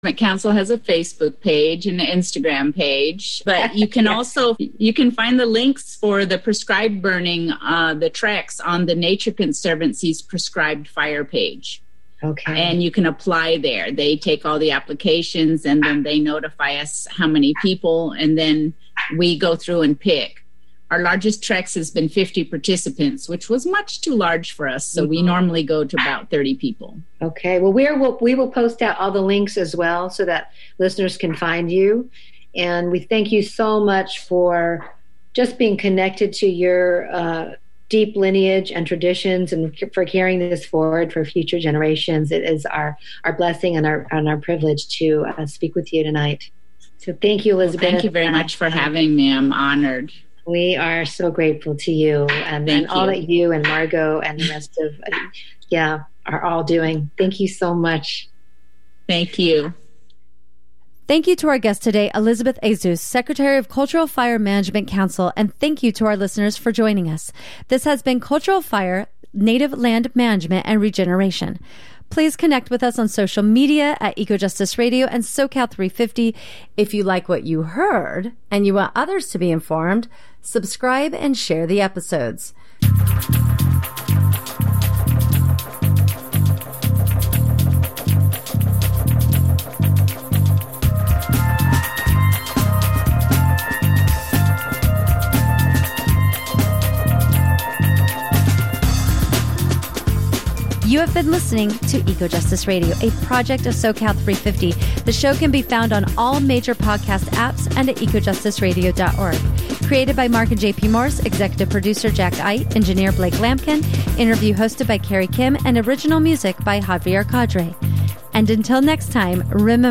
Discussion on Florida's DeSantis, Hurricanes in Puerto Rico, Palestinian Youth Activism